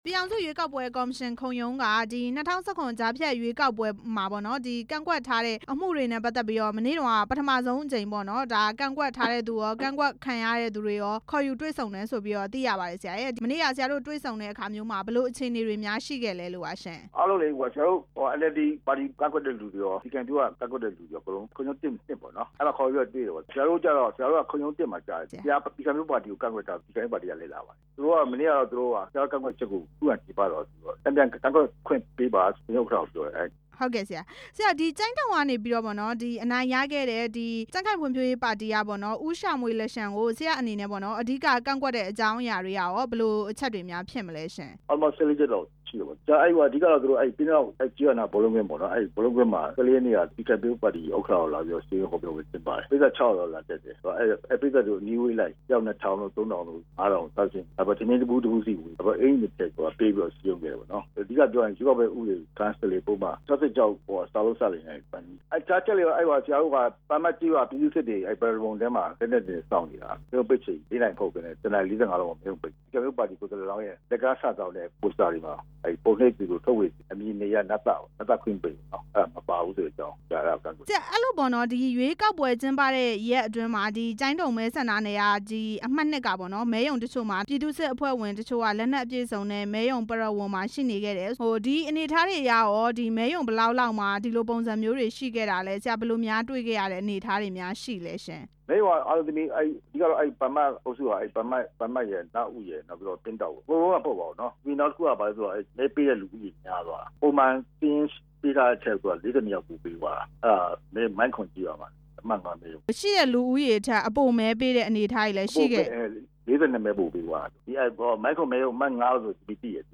ကြားဖြတ်ရွေးကောက်ပွဲ ကန့် ကွက်လွှာ တင်သွင်းမှု မေးမြန်း ချက်